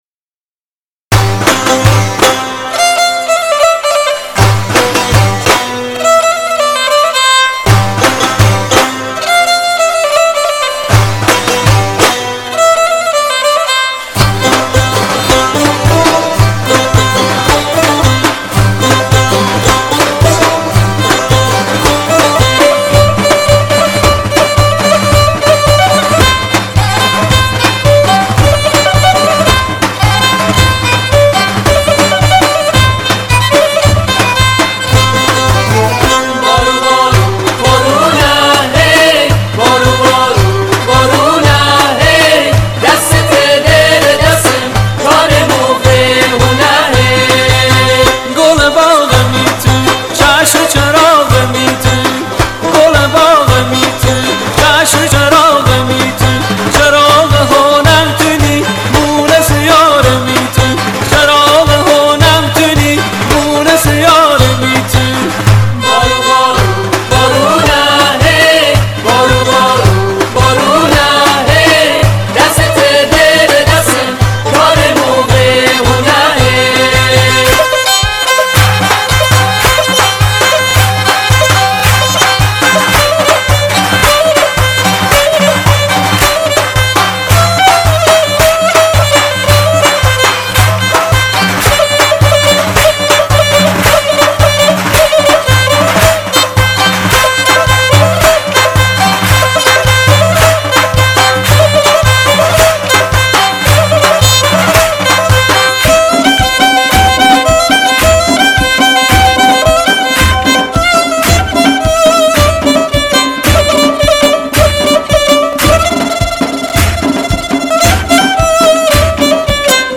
ترانه اصیل لری